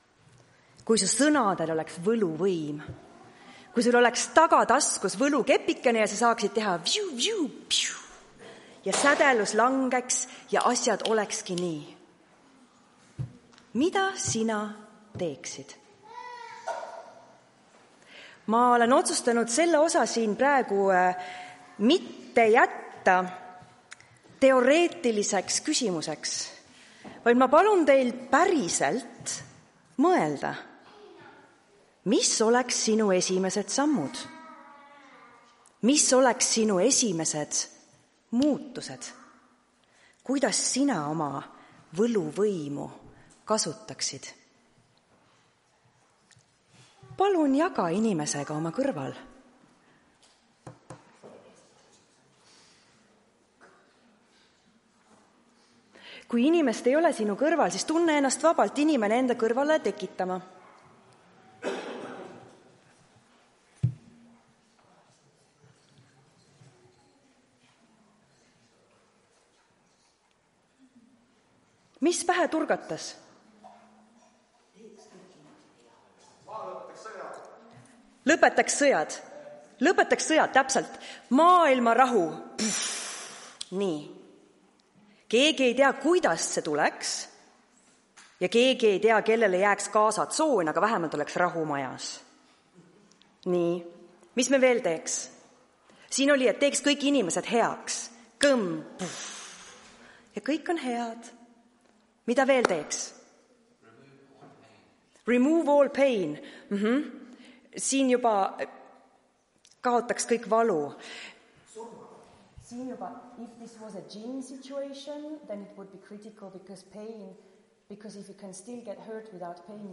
Tartu adventkoguduse 23.08.2025 teenistuse jutluse helisalvestis.